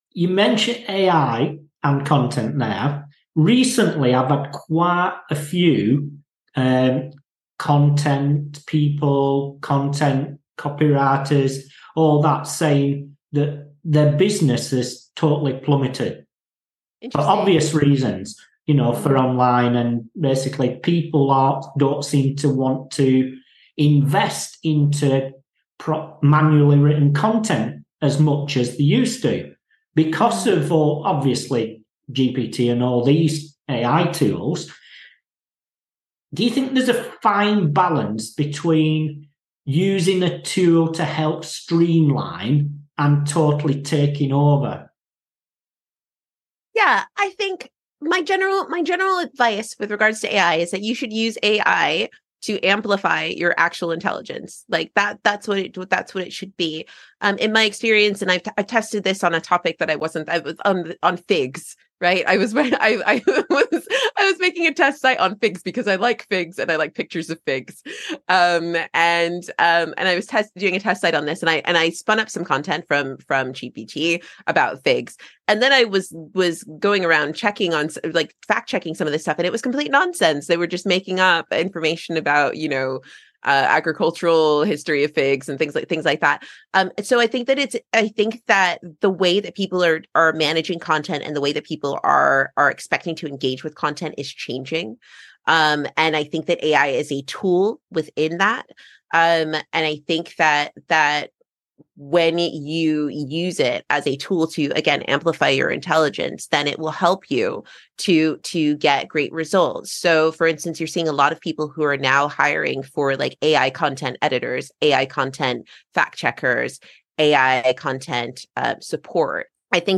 The Unscripted SEO Interview Podcast